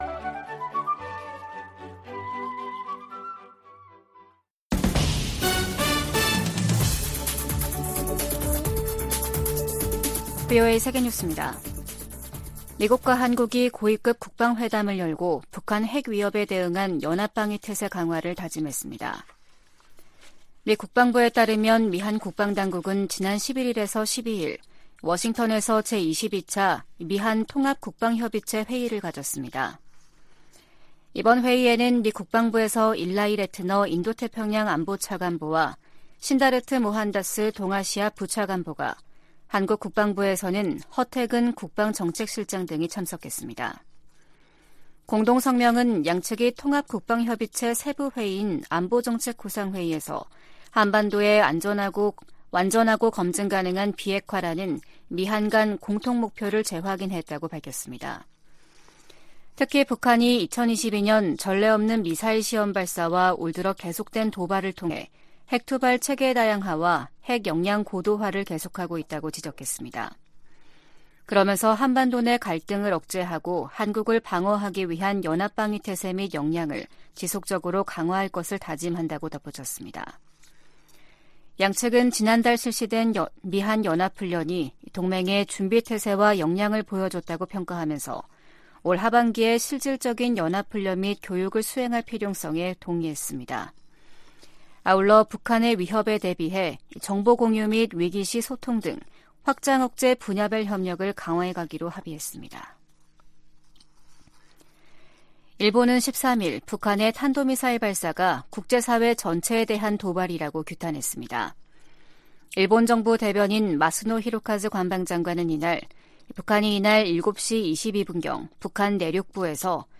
VOA 한국어 아침 뉴스 프로그램 '워싱턴 뉴스 광장' 2023년 4월 14일 방송입니다. 북한이 중거리급 이상의 탄도 미사일을 동해쪽으로 발사했습니다.